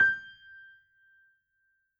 piano_079.wav